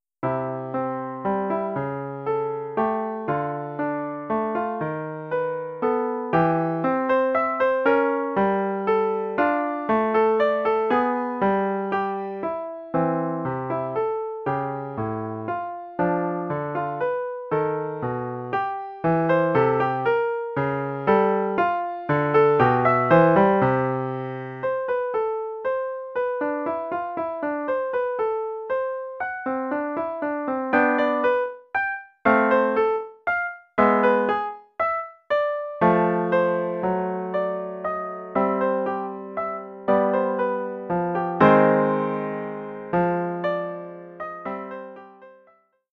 Oeuvre pour piano solo.